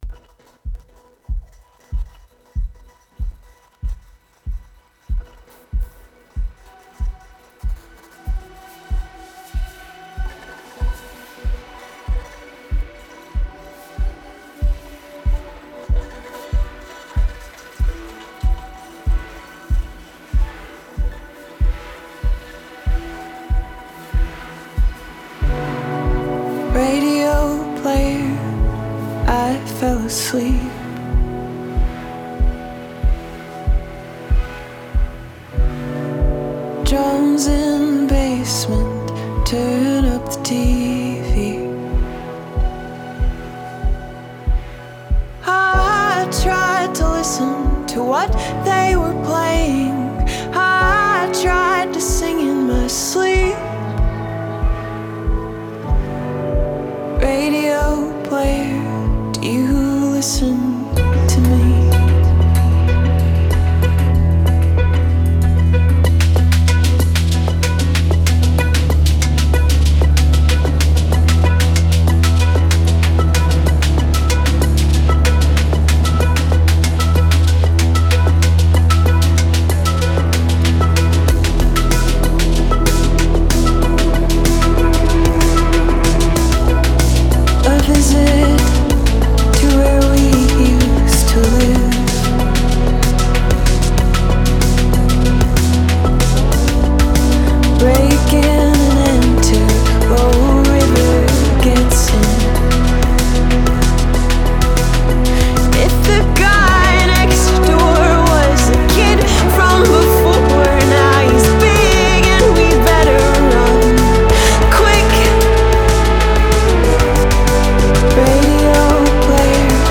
Dynamic mini-journey
Dramatic and expansive
vocals range from tender to penetrating